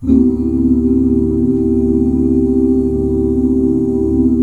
DSUS13 OOO-R.wav